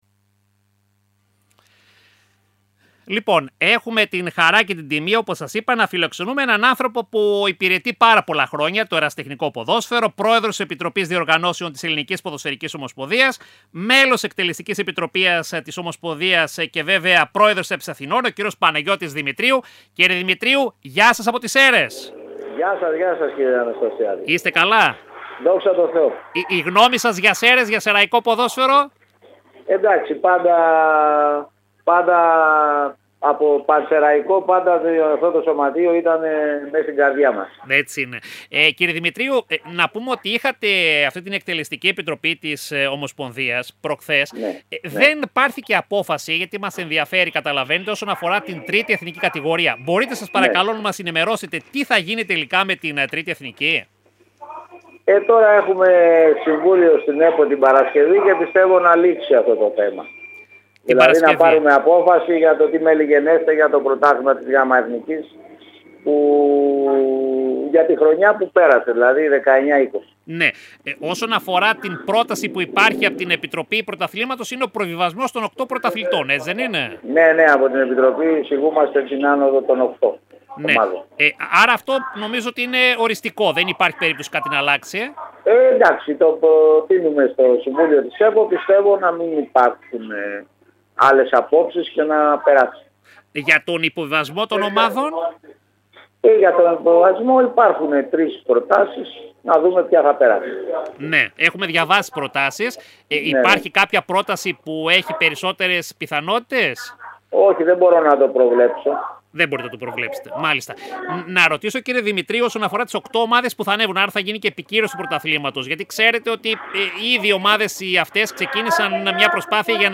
Μέρα κρίσης είναι για την Γ εθνική η Παρασκευή της 29ης Μαΐου σύμφωνα με το όσα είπε σε συνέντευξη του στην αθλητική εκπομπή της ΕΡΤ Σερρών ο πρόεδρος της Επιτροπής Διοργανώσεων της ΕΠΟ, μέλος της Εκτελεστικής Επιτροπής της Ομοσπονδίας και πρόεδρος της ΕΠΣ Αθηνών, Παναγιώτης Δημητρίου.